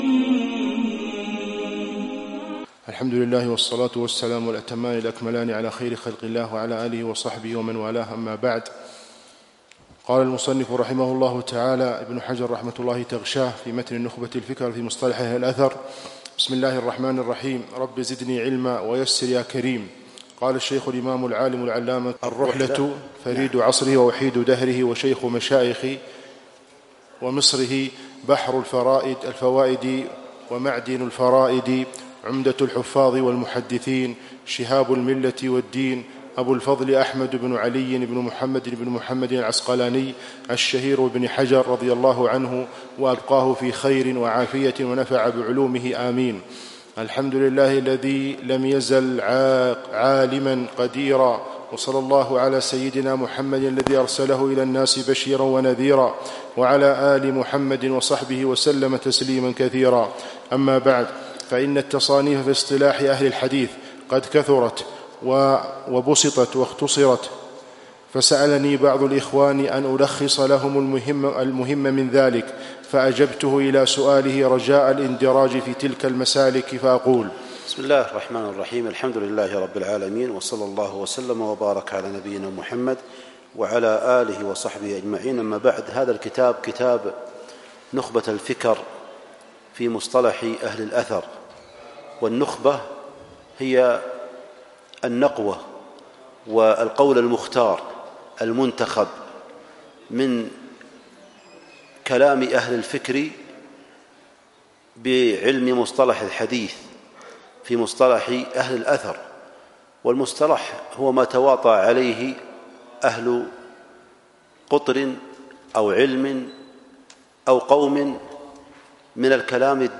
شرح نخبة الفكر في مصطلح أهل الأثر - بجامع العقيل